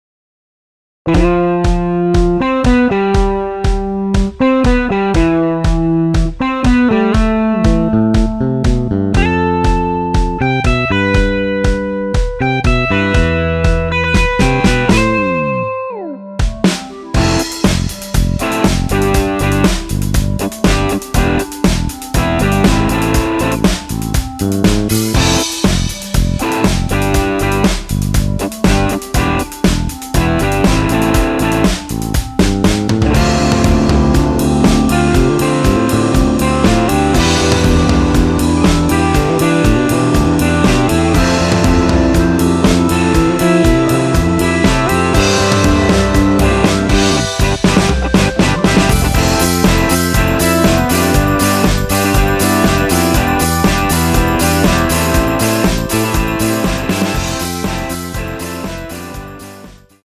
원키 멜로디 포함된 MR입니다.(미리듣기 확인)
앞부분30초, 뒷부분30초씩 편집해서 올려 드리고 있습니다.